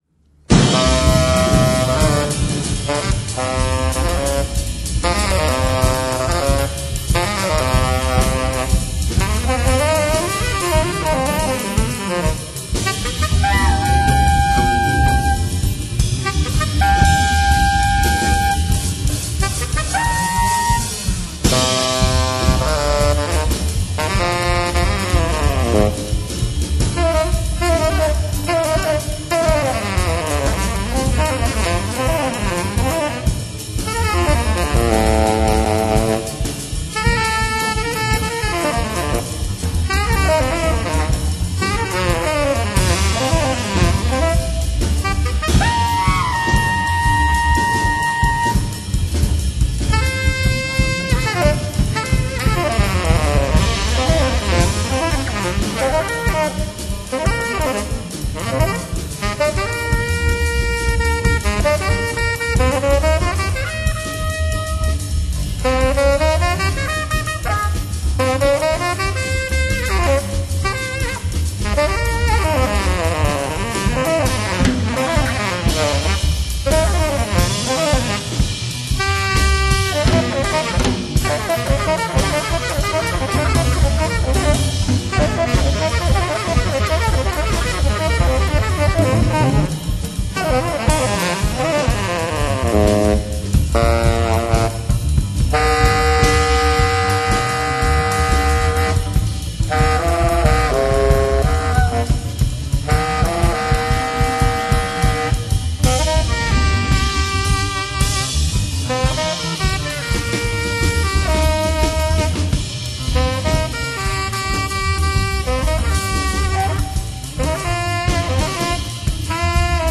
Austrian Radio
Legends of Jazz in Concert.